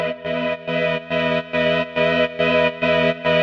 Index of /musicradar/sidechained-samples/140bpm